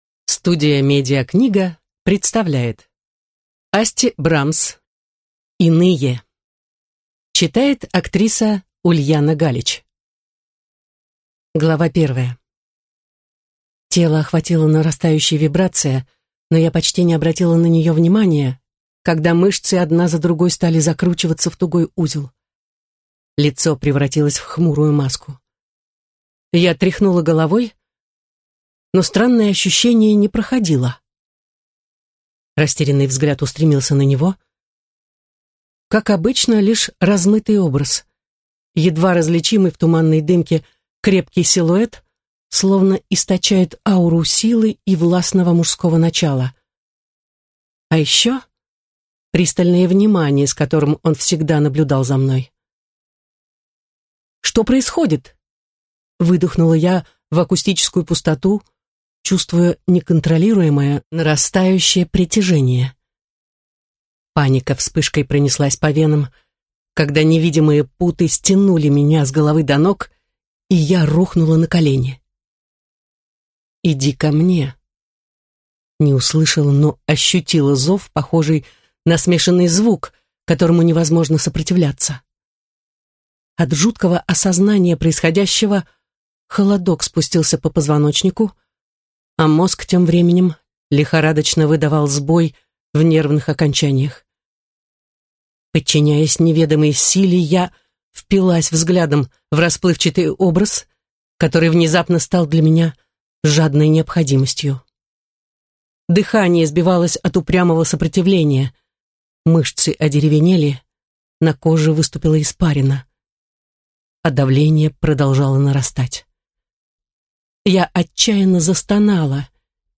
Аудиокнига Иные | Библиотека аудиокниг